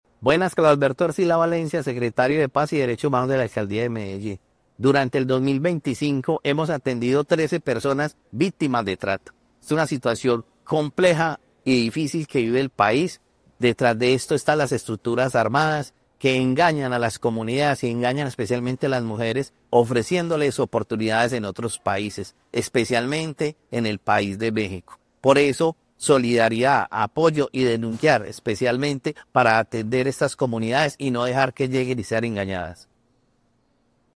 Audio Palabras de Carlos Alberto Arcila, secretario de Paz y Derechos Humanos A través de la Secretaría de Paz y Derechos Humanos se ha implementado el Protocolo de Ruta de Trata de Personas para prevenir, atender y proteger a las víctimas de este grave delito en el Distrito.